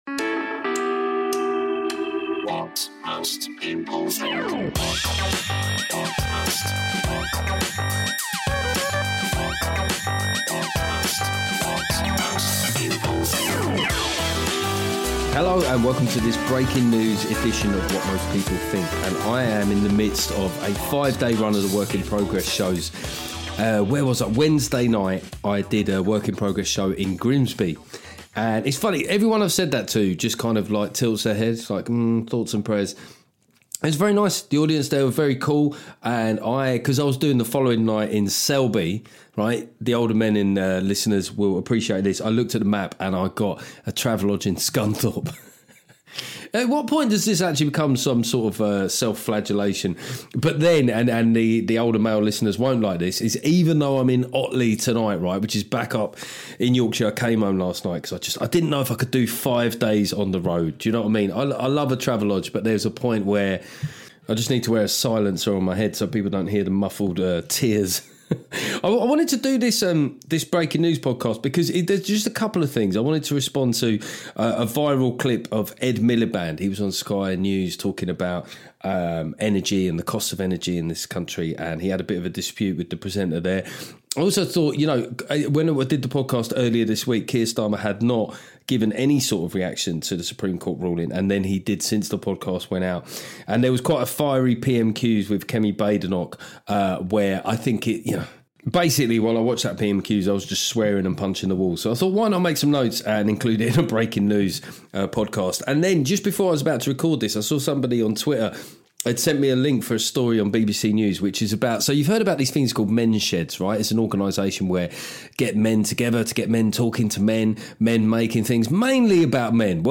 This is a bit of a ranty one. I get my knickers in a twist over Ed Miliband being like a character from Despicable Me. Then I climb into Starmer for yet more shapeshifting. Then I have a right old moan about some women who couldn't bear to see their husbands having fun.